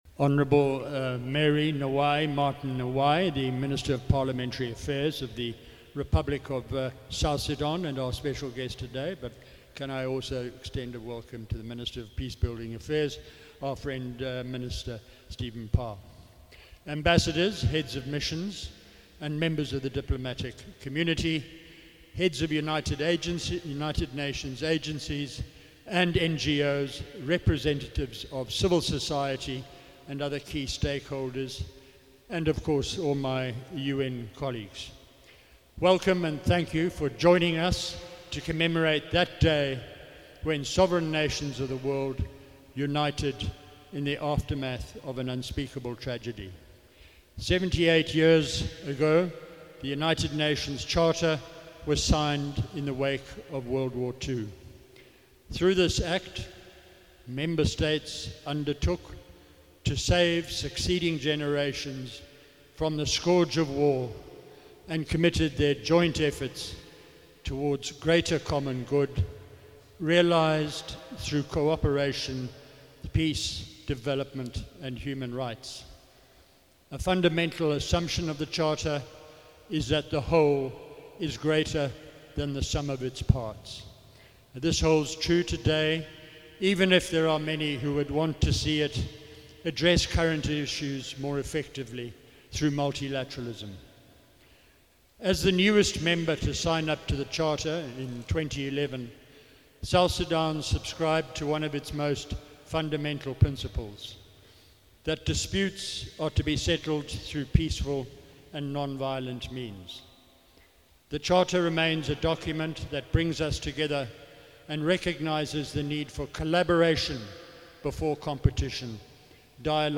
Head of UNMISS Nicholas Hayson speech on UN Day 2023 at Nyakuron Cultural Centre Juba